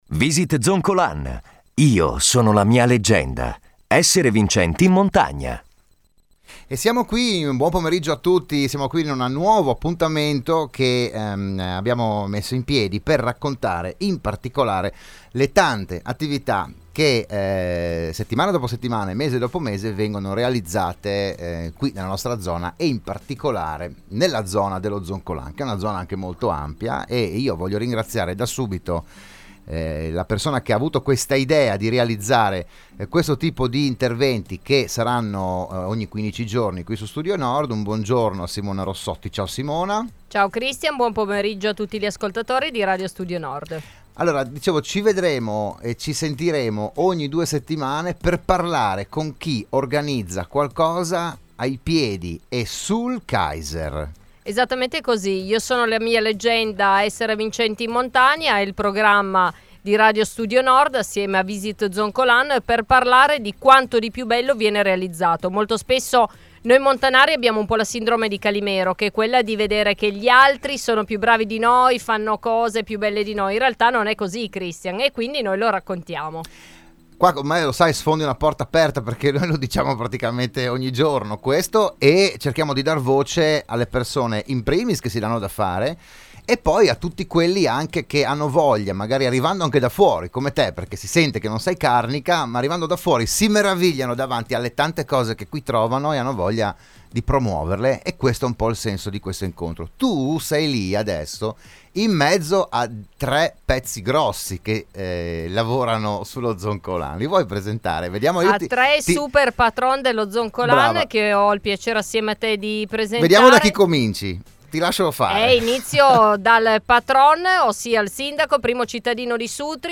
in studio il sindaco di Sutrio Manlio Mattia, l’assessore Daniele Straulino